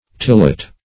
Search Result for " tillot" : The Collaborative International Dictionary of English v.0.48: Tillot \Til"lot\ (t[i^]l"l[o^]t), n. A bag made of thin glazed muslin, used as a wrapper for dress goods.